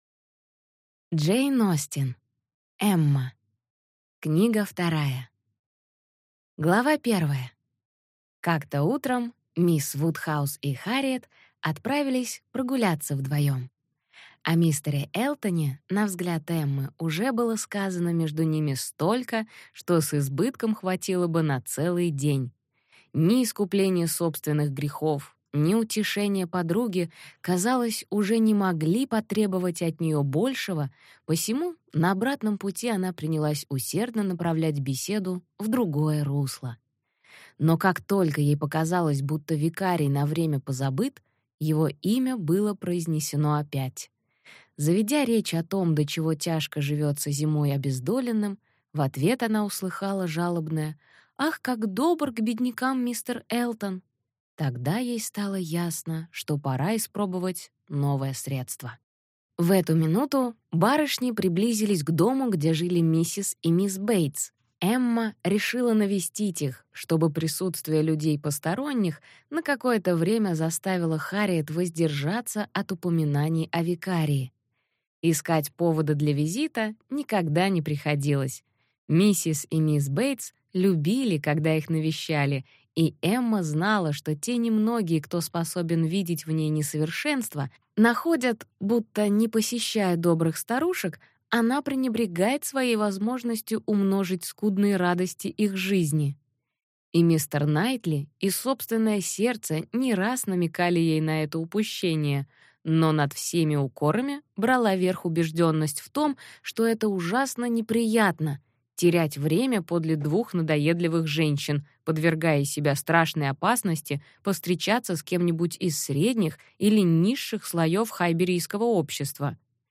Аудиокнига Эмма. Книга 2 | Библиотека аудиокниг